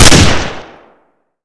Original Sounds / weapons
famas-1.wav